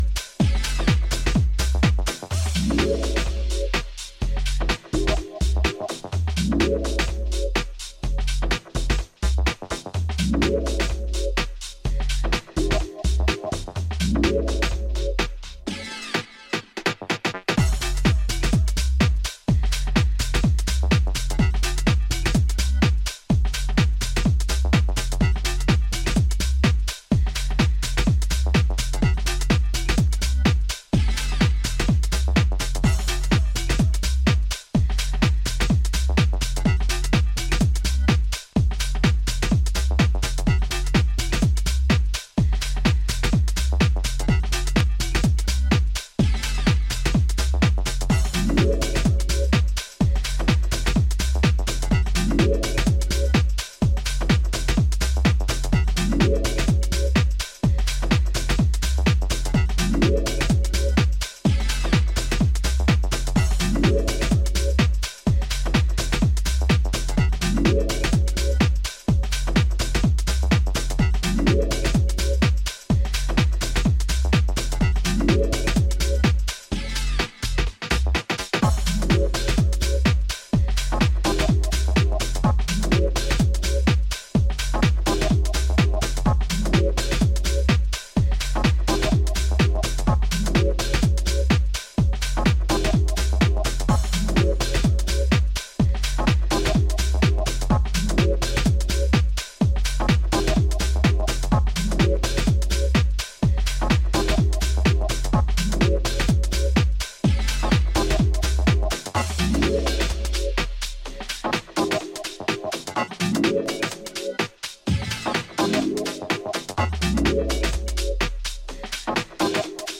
bouncier garage demeanour